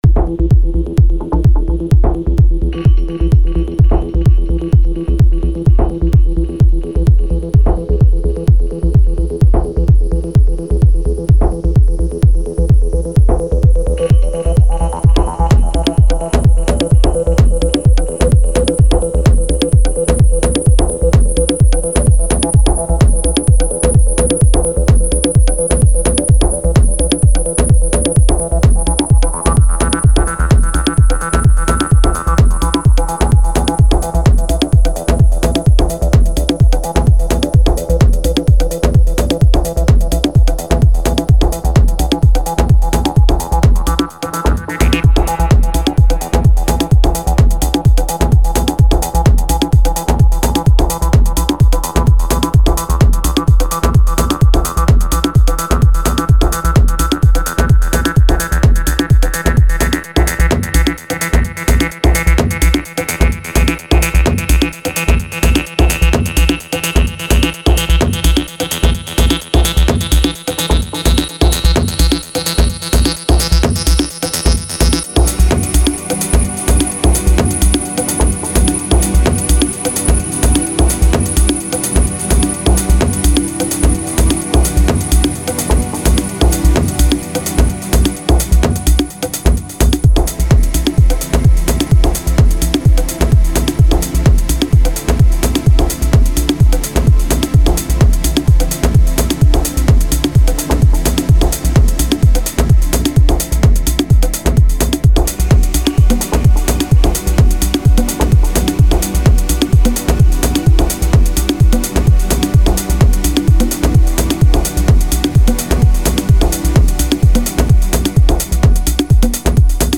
Had a session with my Tanzbär 2 before I’m selling it.
I really love how it sounds and grooves.
Recorded straight into Deluge.
Added pads and some atmo from the Deluge, but otherwise just Tanzbär.
I think I should’ve added some sub as well, but didn’t feel like it.